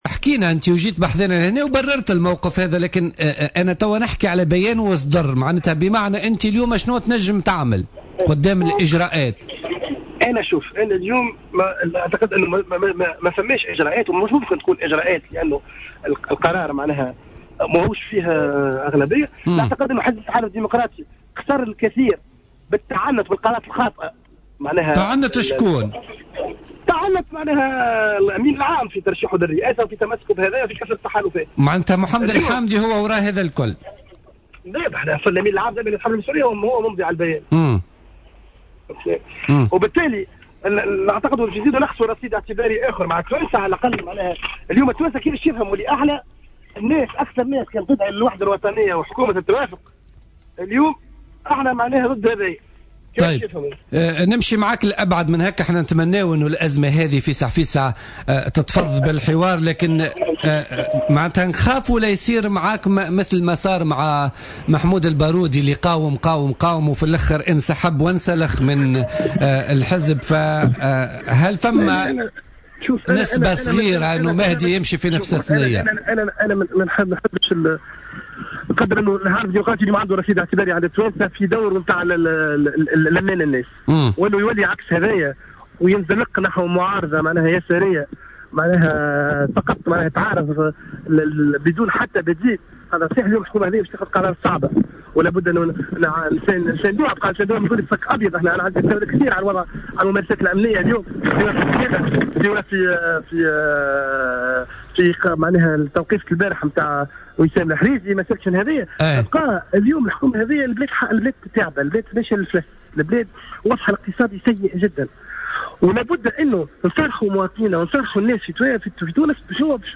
قال نائب مجلس الشعب،مهدي بن غربية في مداخلة له اليوم في برنامج "بوليتيكا" إن حزب التحالف الديمقراطي ليس بإمكانه اتخاذ أي إجراءات ضدّه على خلفية منحه الثقة لحكومة الحبيب الصيد،واصفا قرار المجلس الوطني المجتمع مؤخرا بالغير قانوني نظرا لأنه لم يتوفر على النصاب القانوني لاتخاذ القرار .